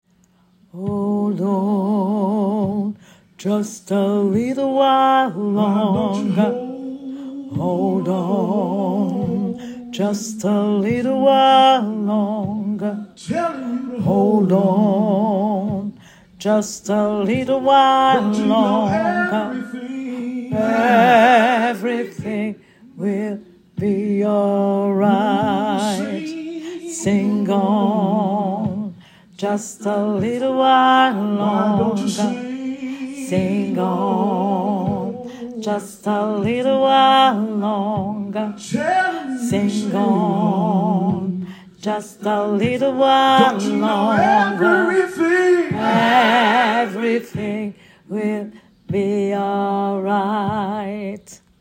Alto / homme
Hold-on-ALTI-HOMMES.mp3